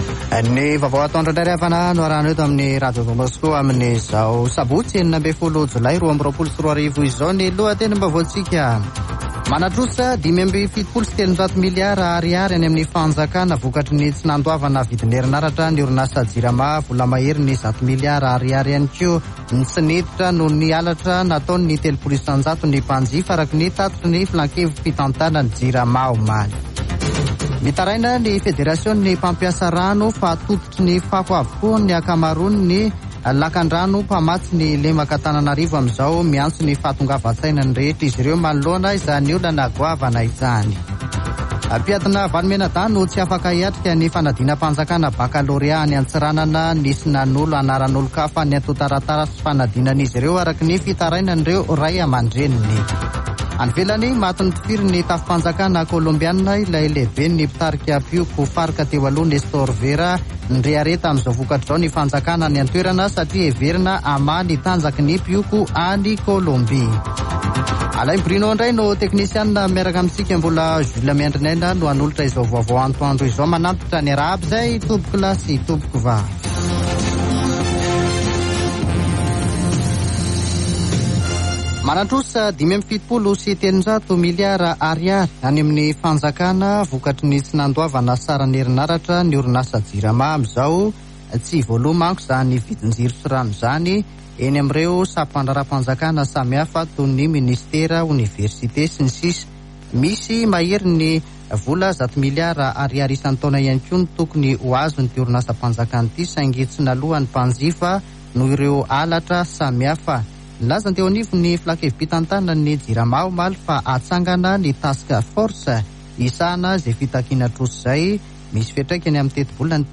[Vaovao antoandro] Sabotsy 16 Jolay 2022